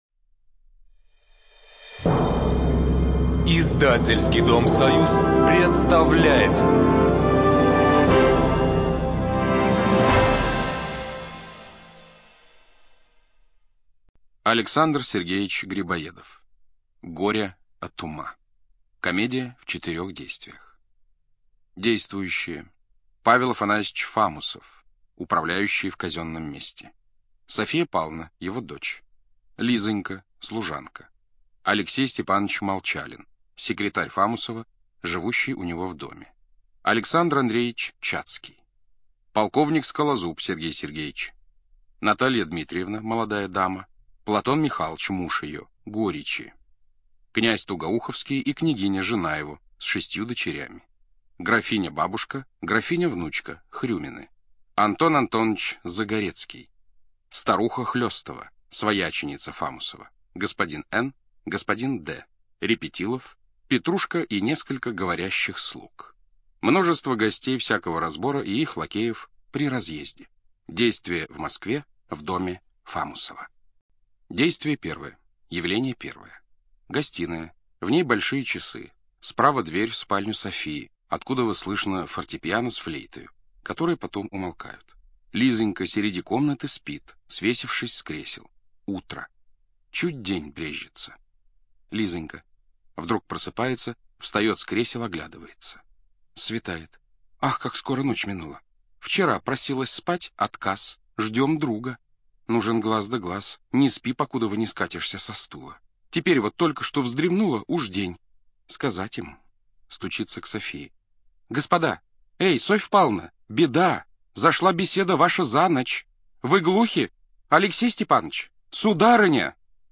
ЧитаетКлюквин А.